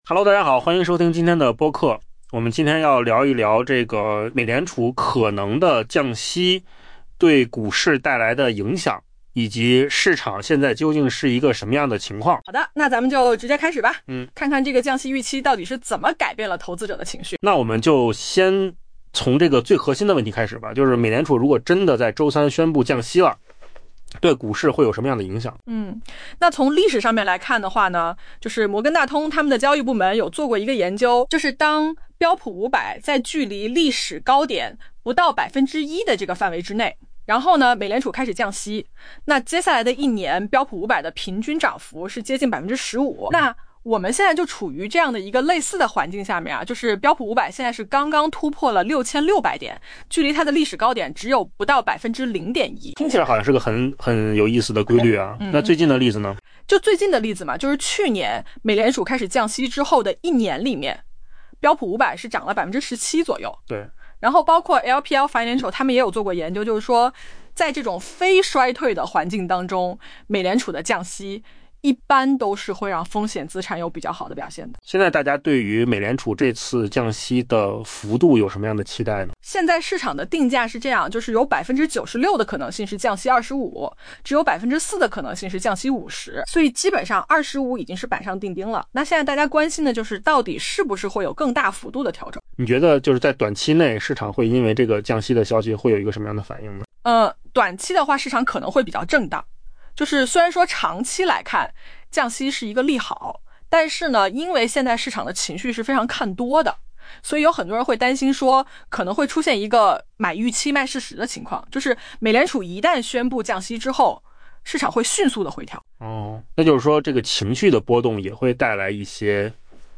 AI 播客：换个方式听新闻 下载 mp3 音频由扣子空间生成 美联储若于北京时间周四凌晨宣布降息，可能会为已然强劲的股市再添动力。